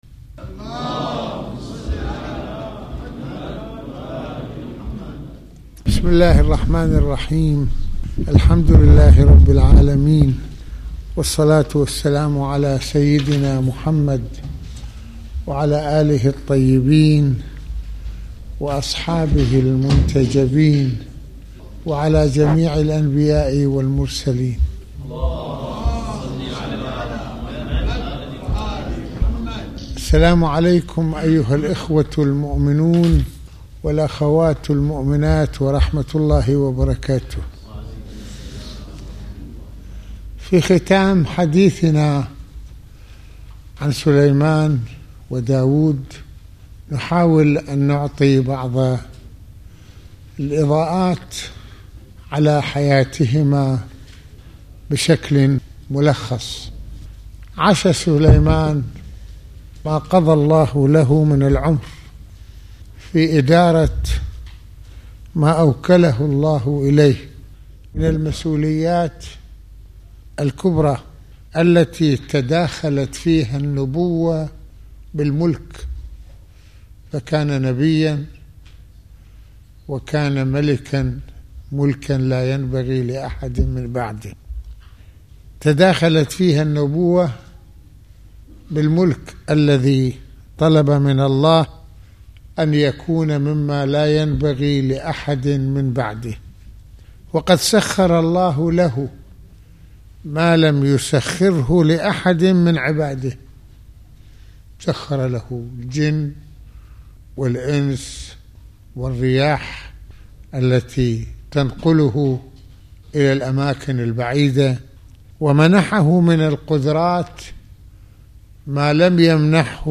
المناسبة : ندوة السبت المكان : الشام - السيدة زينب(ع)